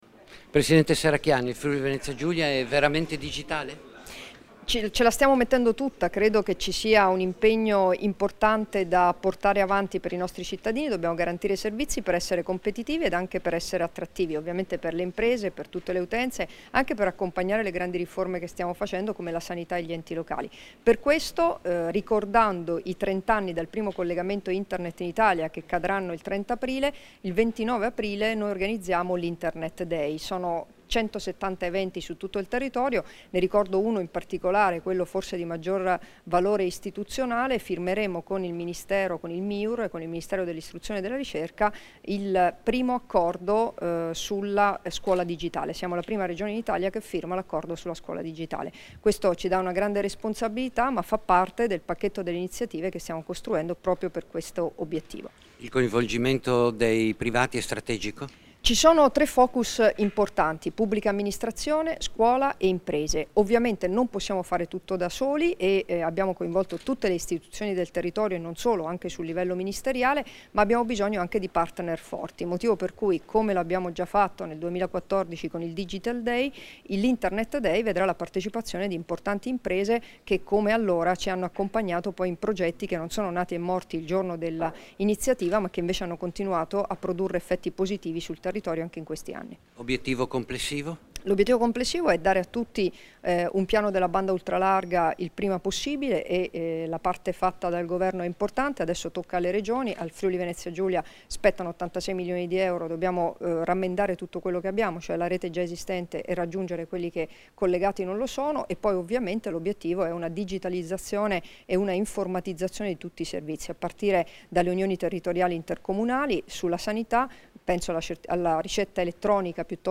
Dichiarazioni di Debora Serracchiani (Formato MP3) [2118KB]
a margine della presentazione dell'evento "internet day fvg / id fvg", rilasciate a Trieste il 12 aprile 2016